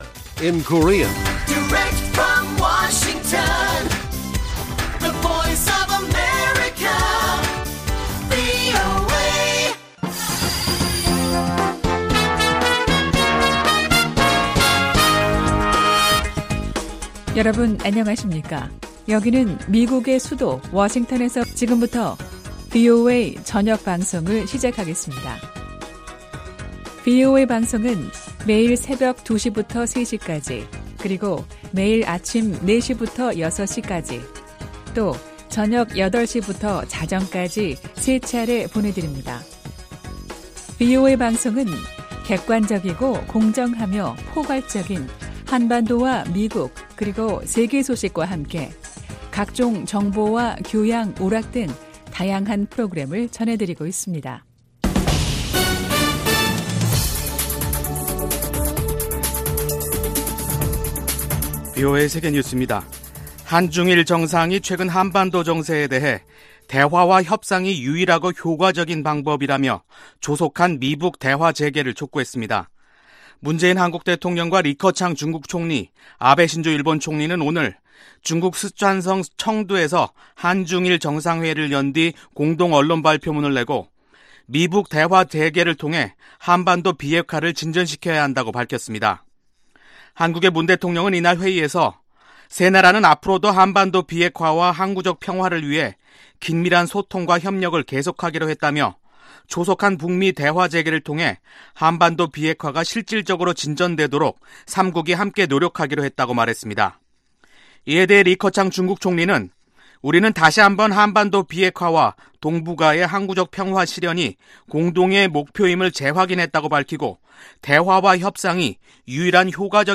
VOA 한국어 간판 뉴스 프로그램 '뉴스 투데이', 2019년 12월 24일1부 방송입니다. 한국과 중국, 일본 세 나라 정상이 북핵 문제의 유일한 해법은 대화와 협상이라는 데 의견을 함께 했습니다. 미 국방부는 홈페이지에 공개했던 미-한 특전사 훈련 영상에 관한 보도에 관해 터무니 없고 위험하다고 밝혔습니다.